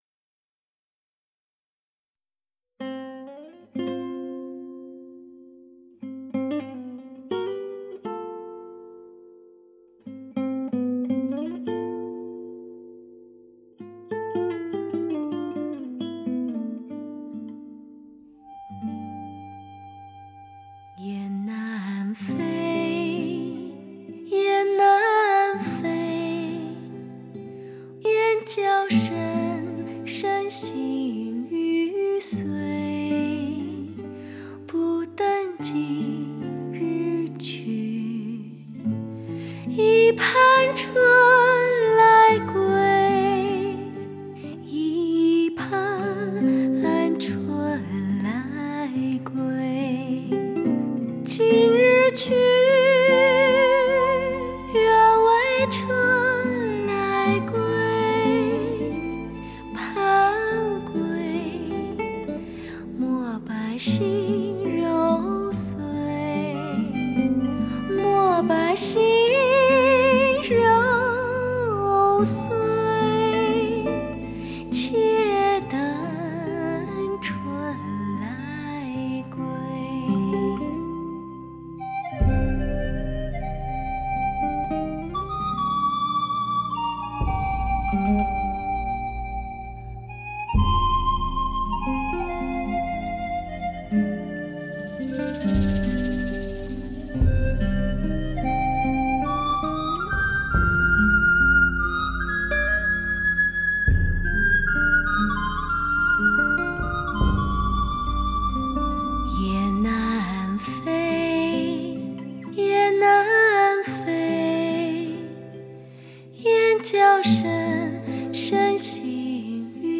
老歌新调、超越经典、冲击你的心灵、尽善
采用最新美国DTS顶级编码器，带来超乎想象震撼性环绕声体验。
达到的诸多效果，DTS音乐真正克服了常规格式CD的声音萎缩和模糊情况。